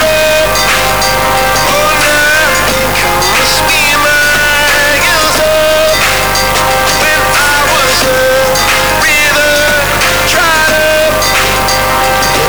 我们可以使用以下 amixer 命令进行录制、但 在播放该音频(.wav)文件时只能听到噪声。
听到的是、我们正在通过 RCA 插孔使用 MIC。
目前、当我们听到捕获的音频时、我们听到了很多噪音。